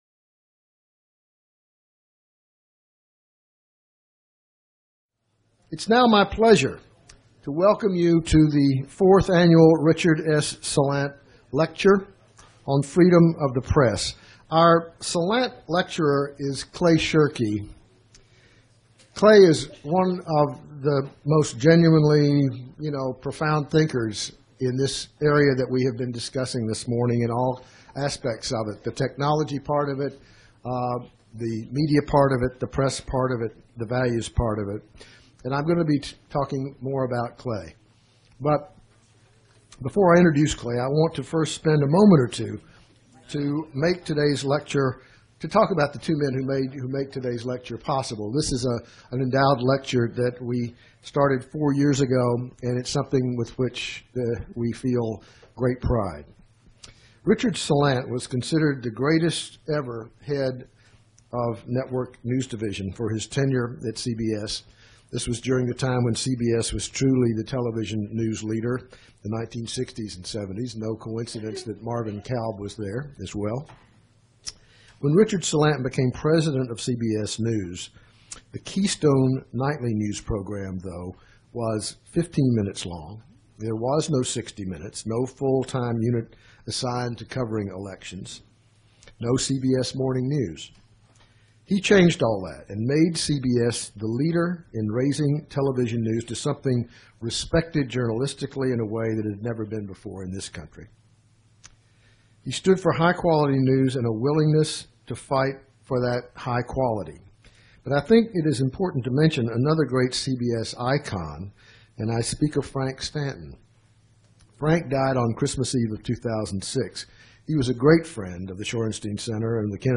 In October 2011, the well-known internet expert Clay Shirky, a professor of New Media at New York University, has given a remarkable lecture on the state of Freedom of the Press in Western democracy. He makes a comparison between the technical, territorial, political and legal implications of the Watergate scandal, the recent publications of Collateral Murder and the documents of Cablegate that were published online by Wikileaks.